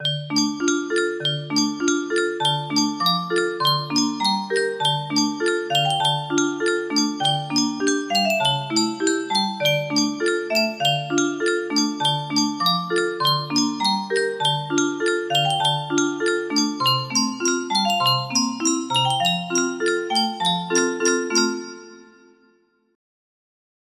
Unknown amonoh music box melody
Full range 60